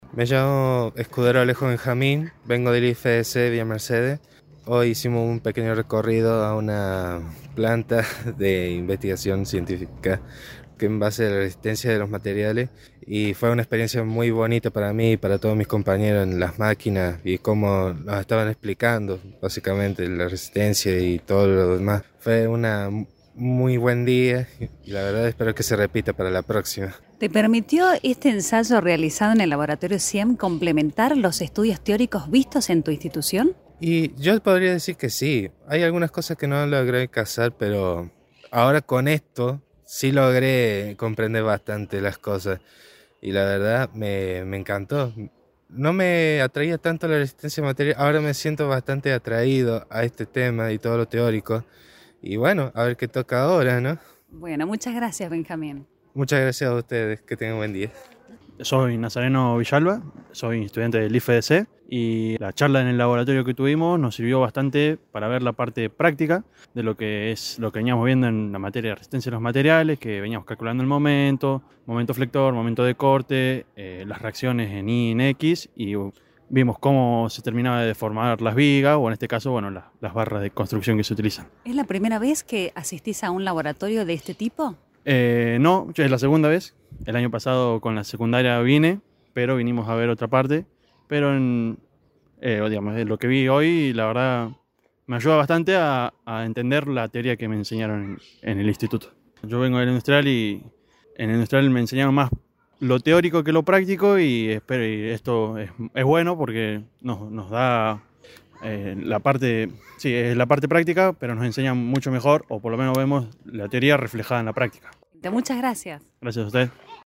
Estudiantes IFDC en el CIEM.mp3